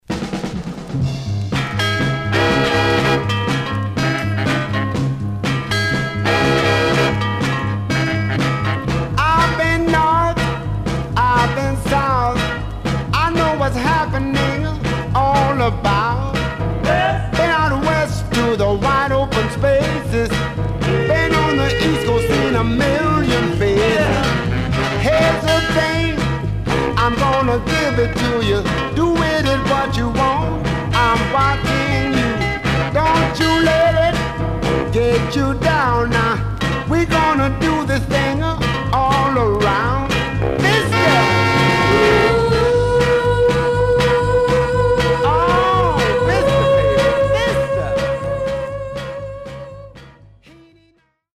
Mono
Soul